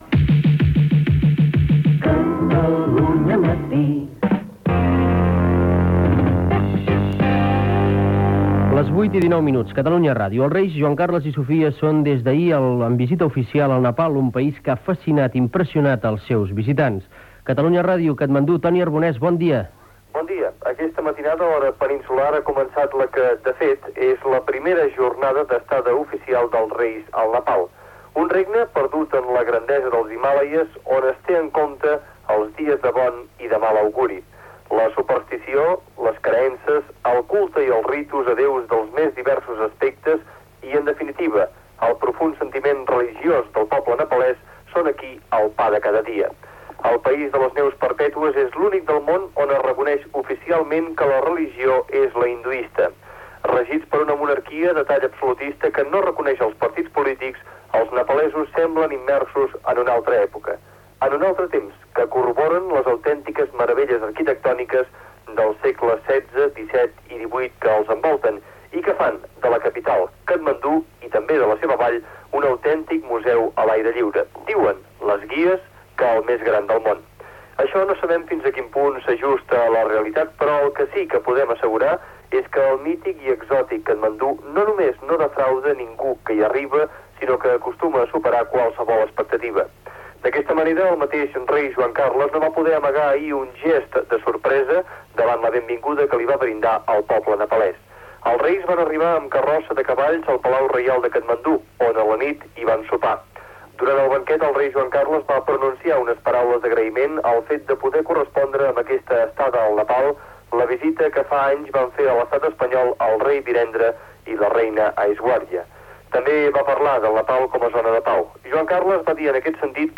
Indicatiu del programa, hora, identificació de l'emissora, informació, des de Katmandú, del primer dia de la visita dels reis d'Espanya Juan Carlos I i Sofia al Nepal (paraules de Juan Carlos I)
Informatiu